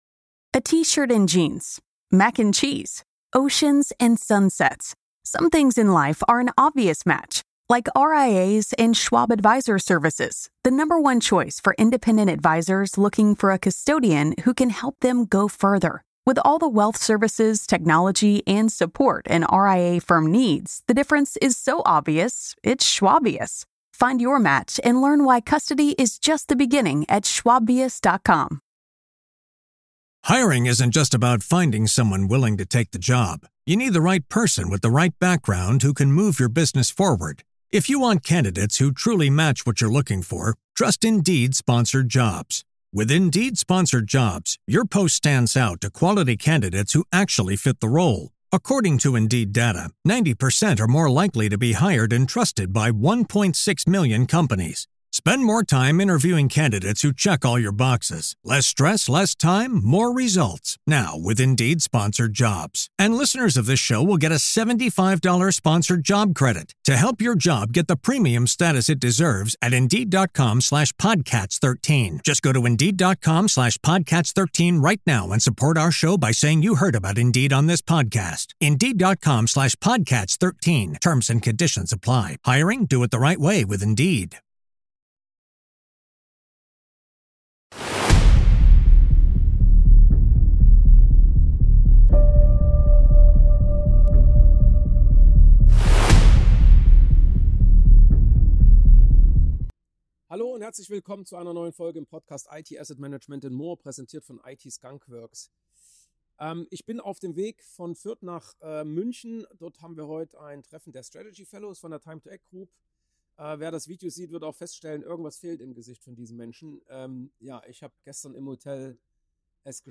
Projekt vs. Experiment vs. Routine vs. Chaos, das magische Dreieck aus Zeit/Kosten/Qualität – und warum Menschen & Kommunikation am Ende wichtiger sind als jede Methode. In dieser Folge bin ich „on the road“ nach München (Treffen der TIMETOACT GROUP Strategy Fellows) – und ja, meine Brille ist gestern zerbrochen…